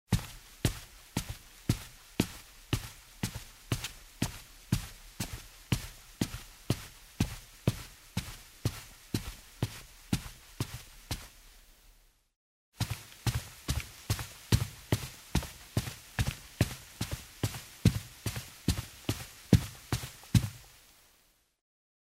Звуки шагов по лестнице
На этой странице собраны разнообразные звуки шагов по лестнице: от легких шагов на деревянных ступенях до тяжелых шагов по бетону.